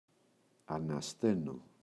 ανασταίνω [ana’steno] – ΔΠΗ